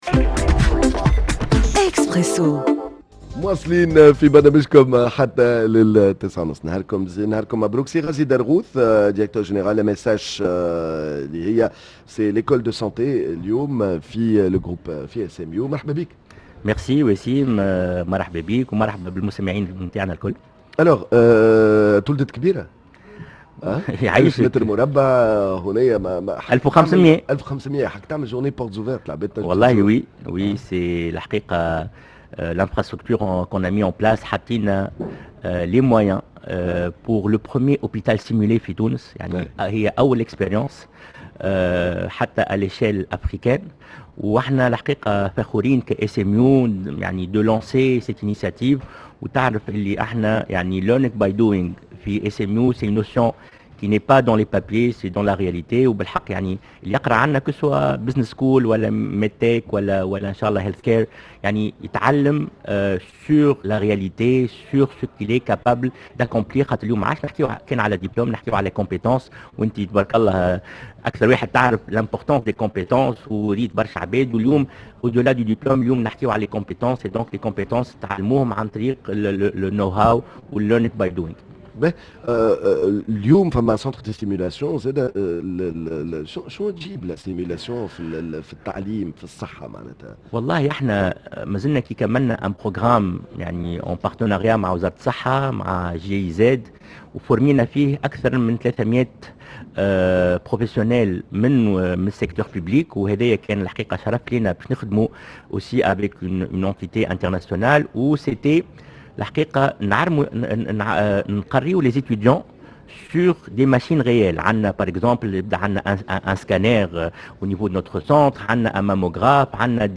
lors de la 11ème édition du « SMU Career Fair » sous le thème « AI for Sustainability »